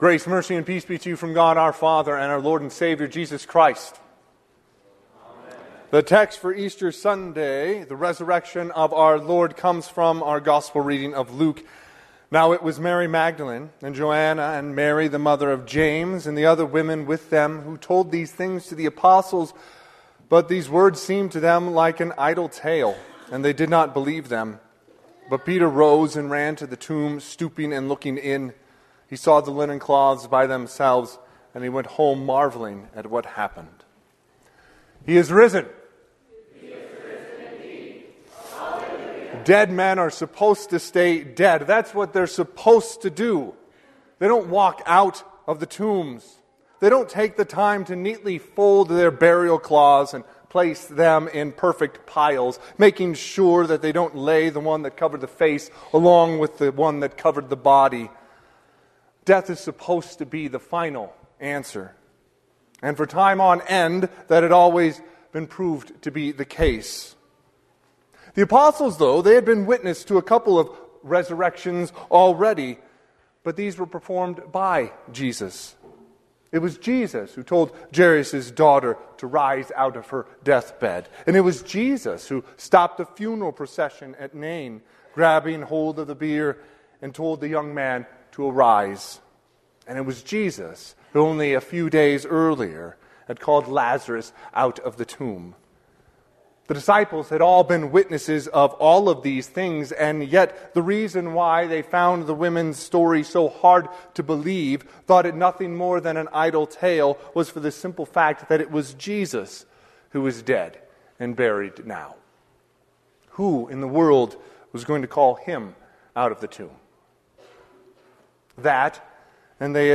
Easter Sunday - 10AM